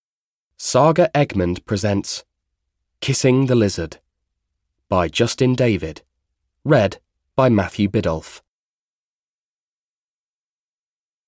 Kissing the Lizard (EN) audiokniha
Ukázka z knihy